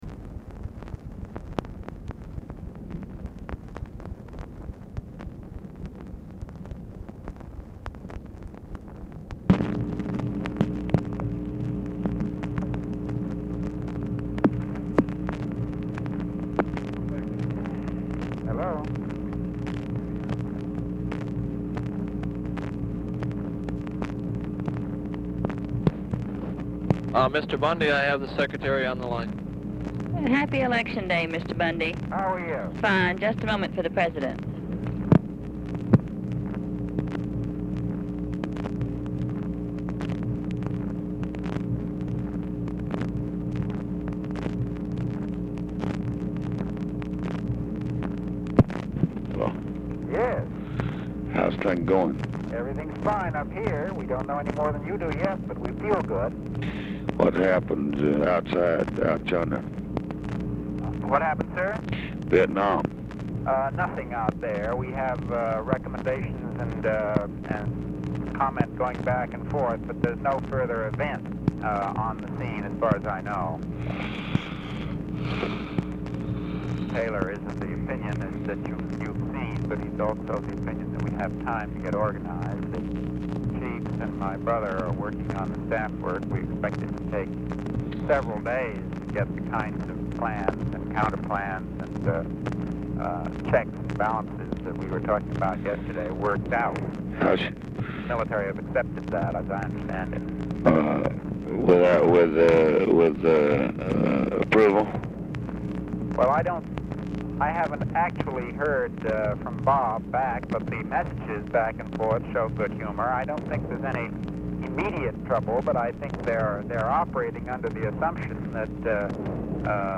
Telephone conversation
BUNDY ON HOLD 0:50; LBJ SOUNDS HOARSE, TIRED; OFFICE SECRETARY WISHES BUNDY HAPPY ELECTION DAY
Format Dictation belt
Location Of Speaker 1 LBJ Ranch, near Stonewall, Texas